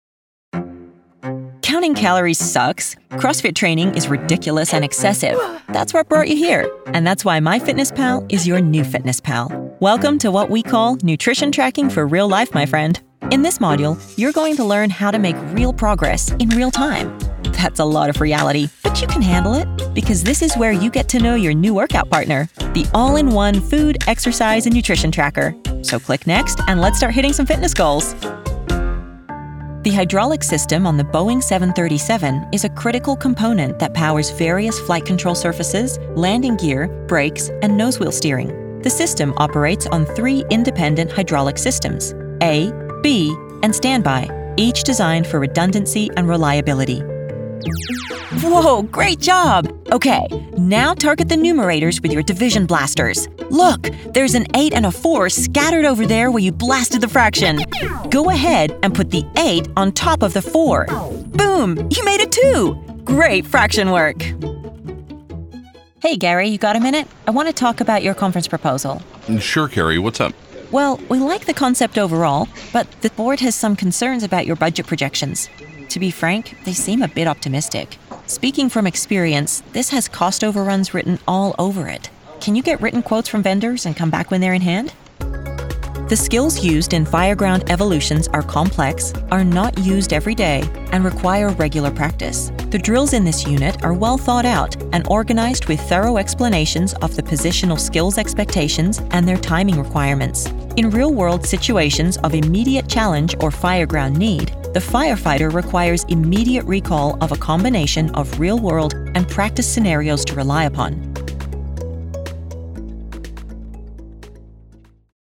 Warm, confident, nuanced, and with range from deep luxury to sarcastic milennial and everything in between.
E-Learning
General American, Neutral Canadian
Young Adult
Middle Aged